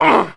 1 channel
WELDER-PUNCHED1.WAV